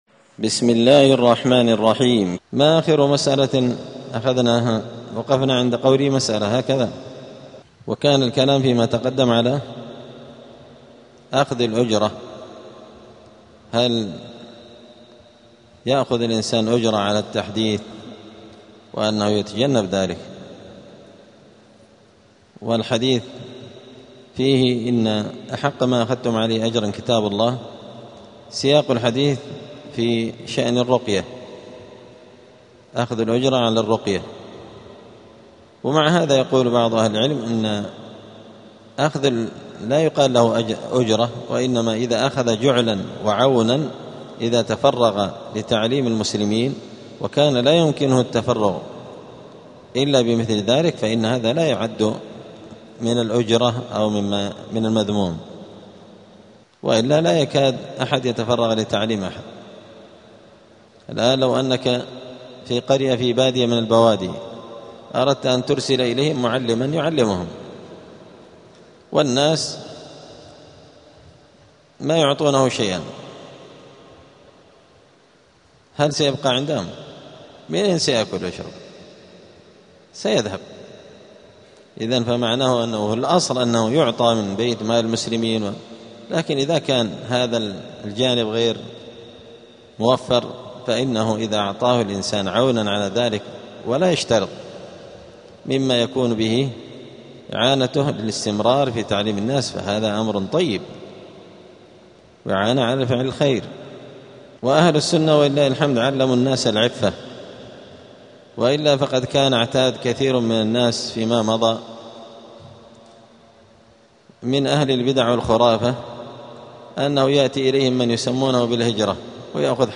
السير الحثيث شرح اختصار علوم الحديث – الدرس الثاني والسبعون (72) : أعلى العبارات في التعديل والتجريح.
دار الحديث السلفية بمسجد الفرقان قشن المهرة اليمن